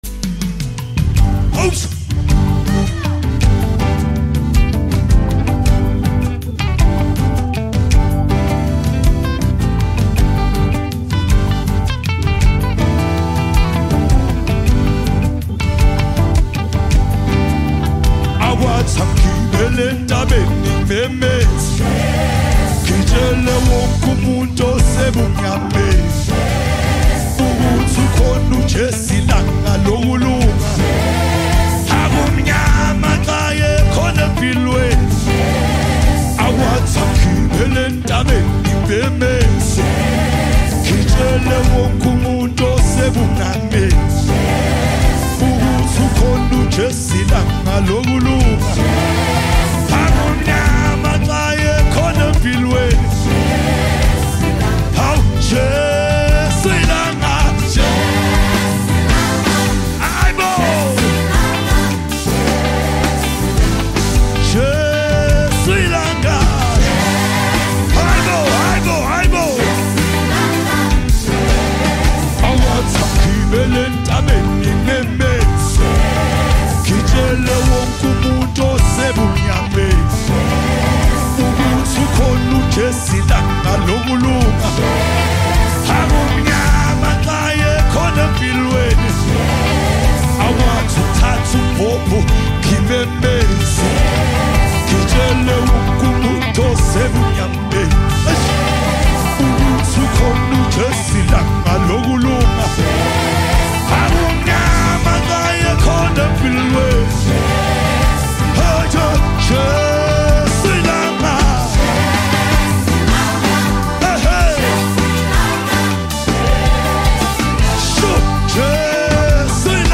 with rich sound, smooth vibes